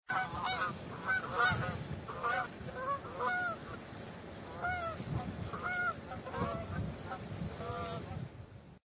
Geese_Honking_(distant).ogg.mp3